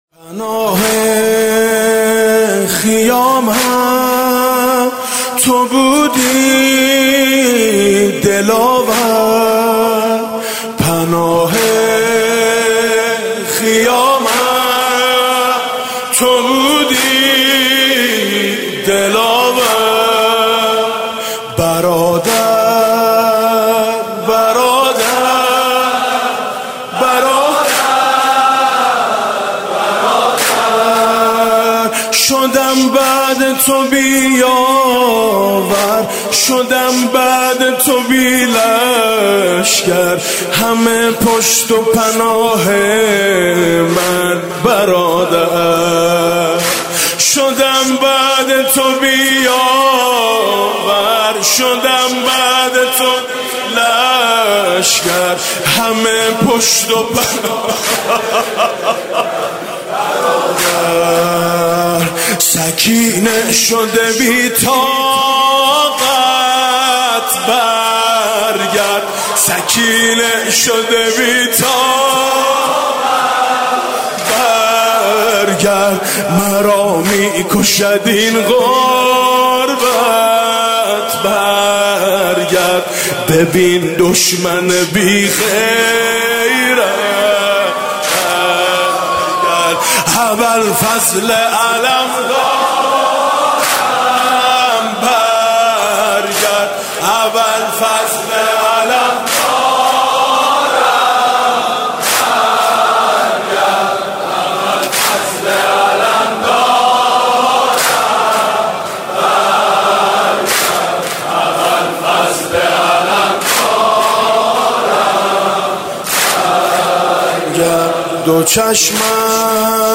شب تاسوعا محرم 97 - هیئت میثاق با شهدا - واحد - گره وا نشد از کار سقا